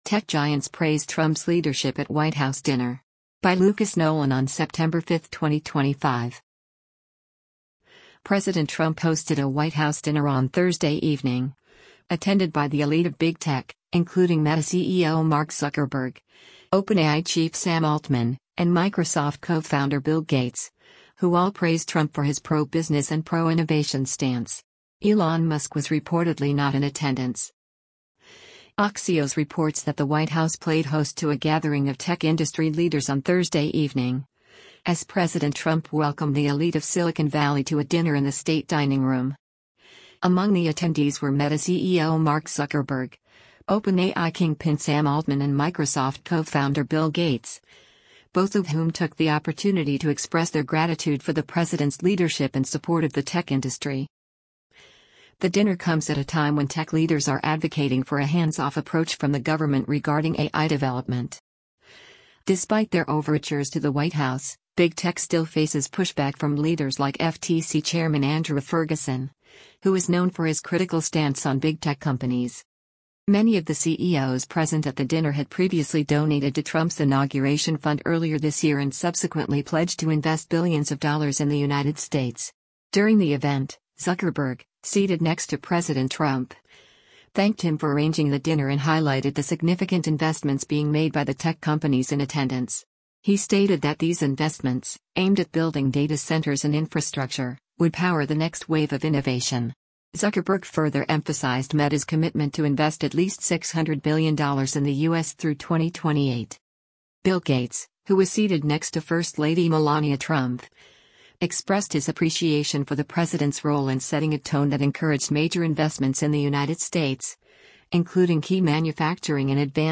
Mark Zuckerberg speaks at Trump's White House dinner